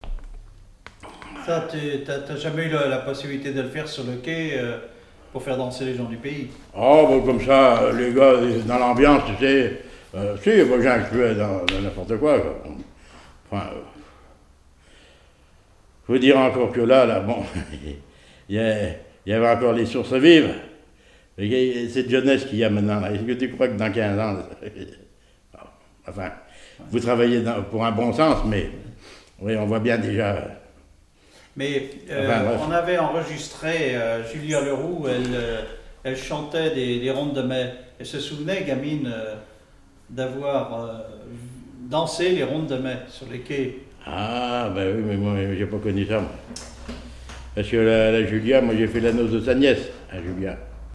Témoignage sur la musique et des airs issus du Nouc'h
Catégorie Témoignage